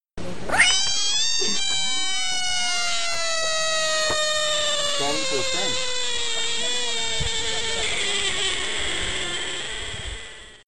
Play, download and share REEEEEE original sound button!!!!
reeeeee.mp3